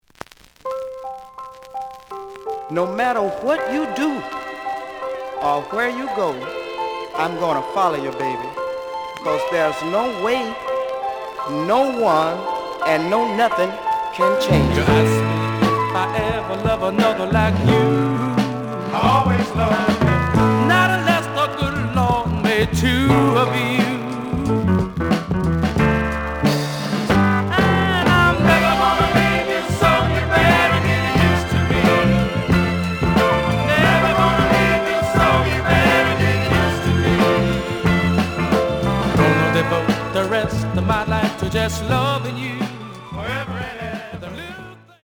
The audio sample is recorded from the actual item.
●Genre: Funk, 60's Funk
Slight edge warp.